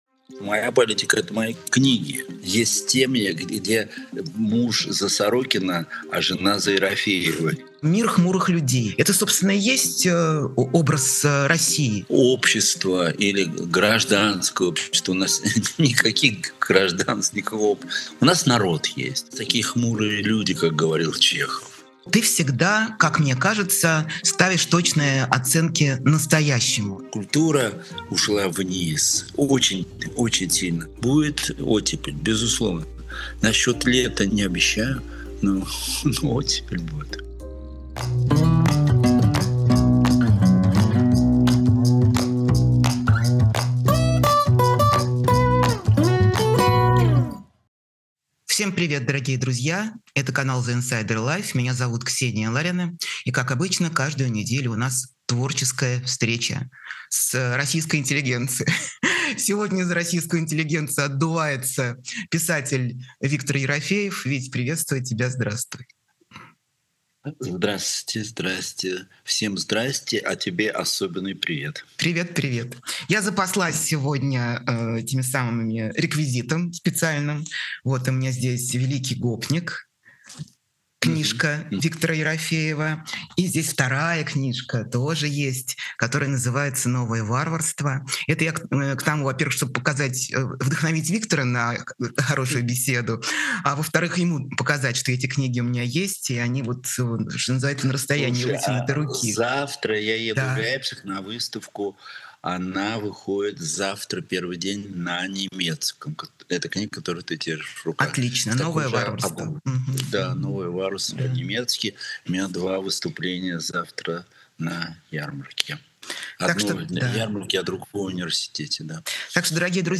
Эфир ведёт Ксения Ларина